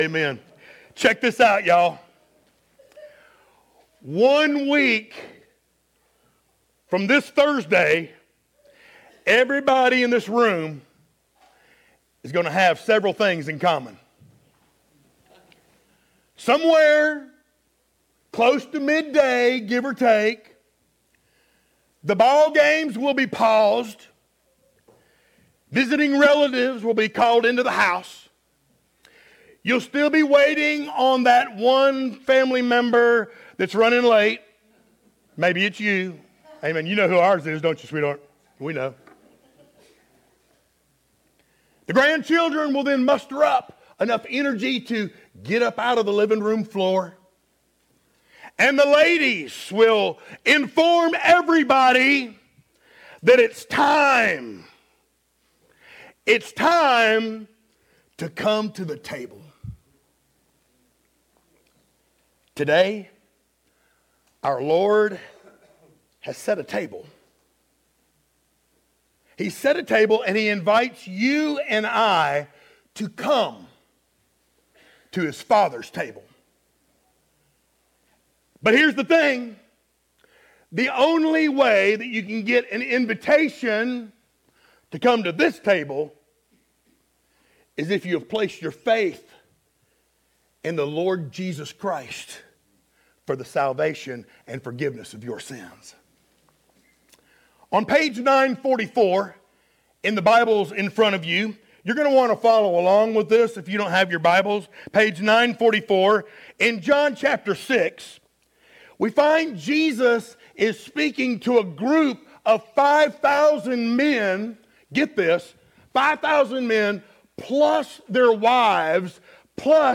Series: sermons